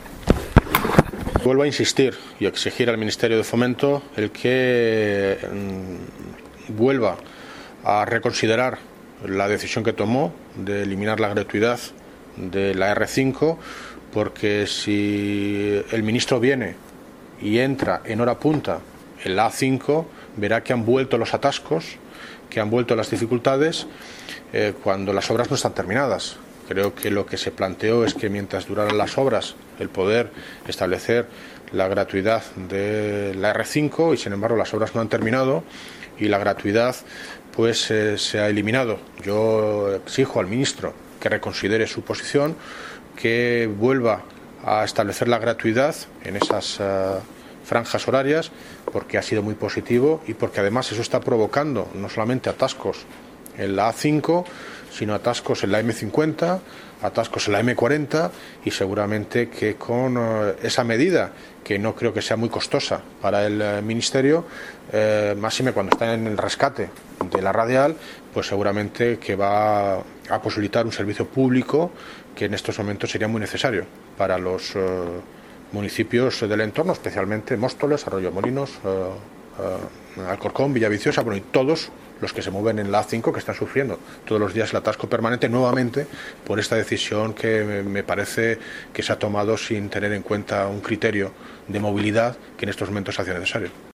Audio - David Lucas (Alcalde de Móstoles) Sobre la R-5